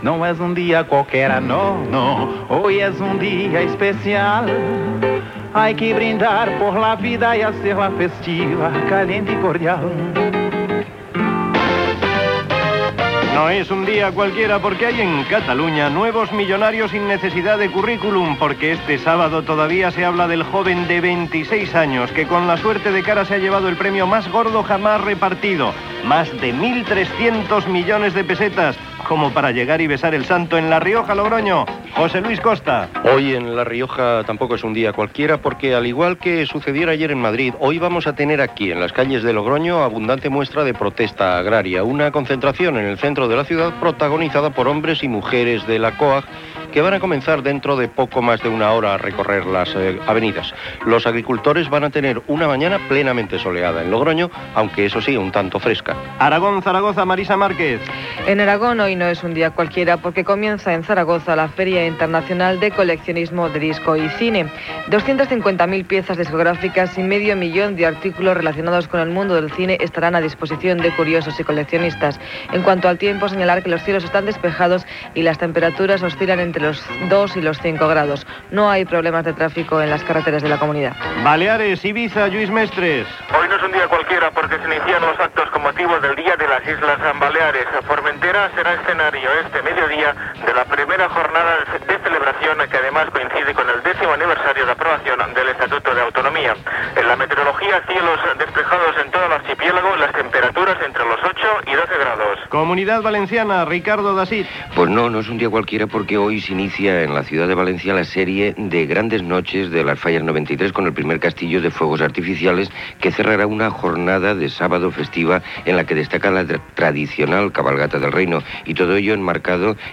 Identificació cantada del programa. Roda autonòmica d'actes, els arbitres de futbol, roda autonòmica d'actes.